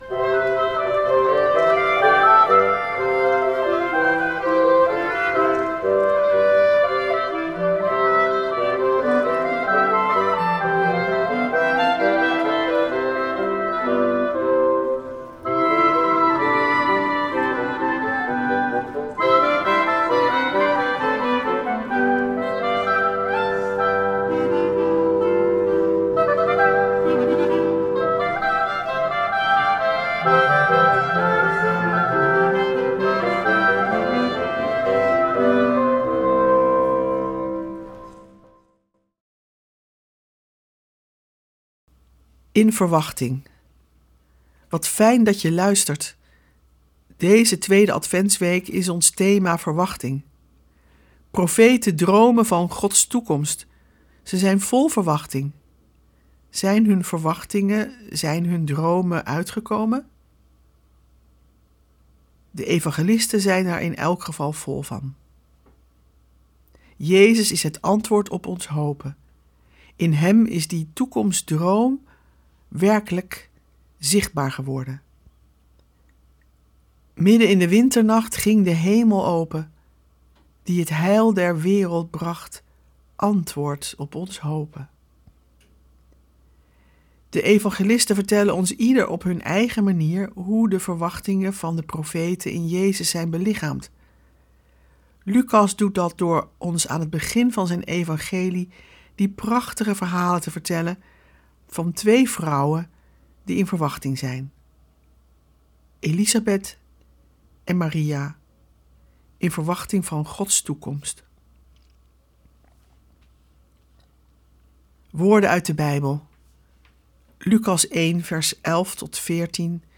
Met muziek en een actuele reflectie.